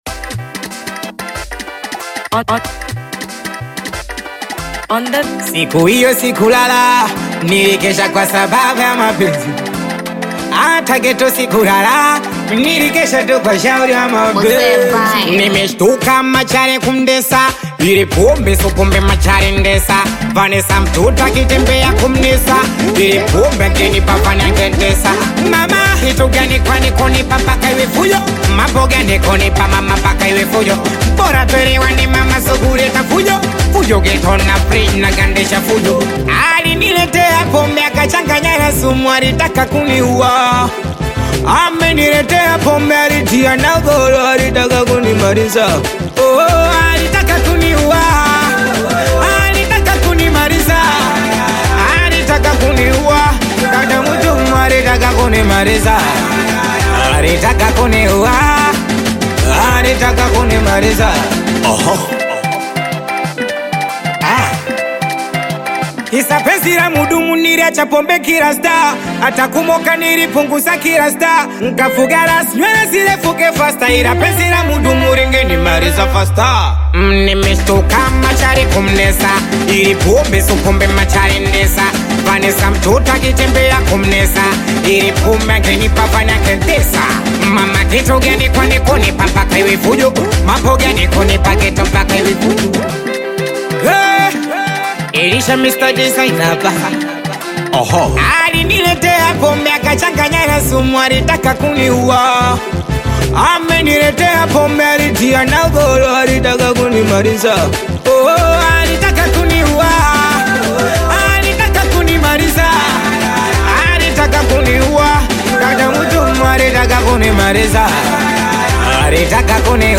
Singeli music track
Bongo Flava